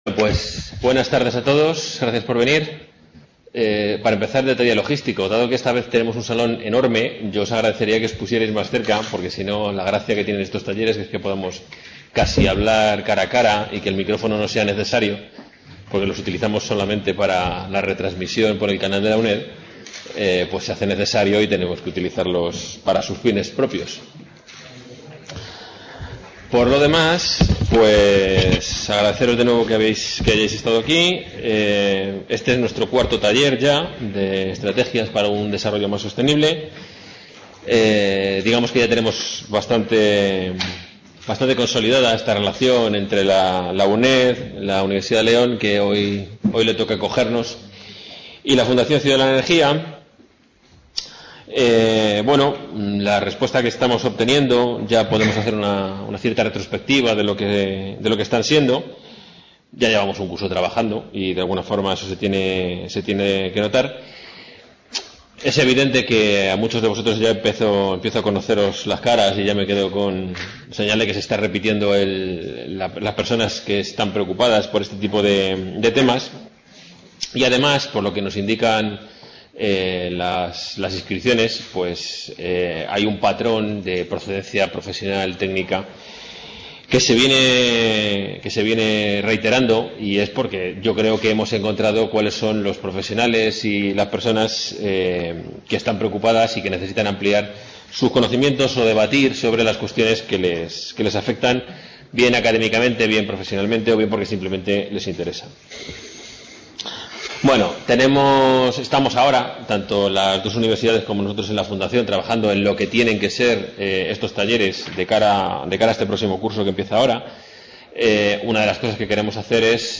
| Red: UNED | Centro: UNED | Asig: Reunion, debate, coloquio... | Tit: CONFERENCIAS | Autor:varios